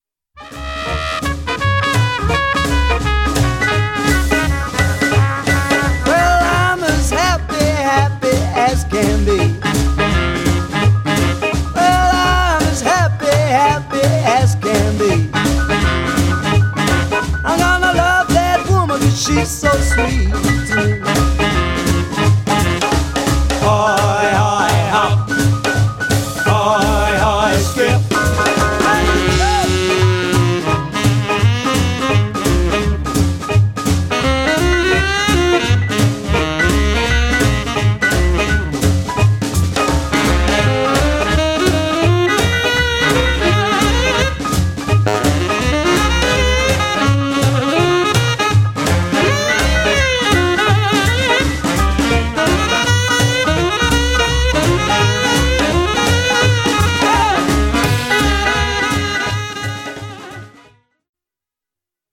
saxophone ténor, chant
trompette
saxophone baryton
piano
contrebasse
batterie